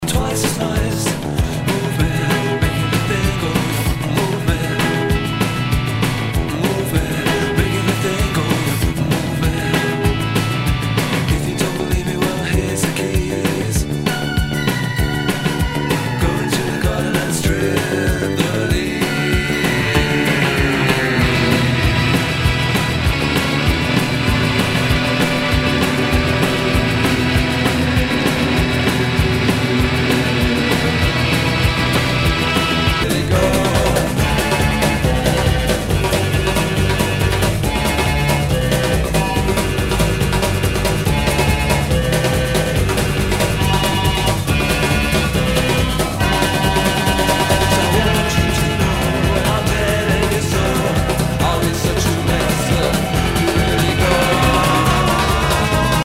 ROCK/POPS/INDIE
ナイス！インディーロック / シンセ・ポップ！
[VG ] 平均的中古盤。スレ、キズ少々あり（ストレスに感じない程度のノイズが入ることも有り）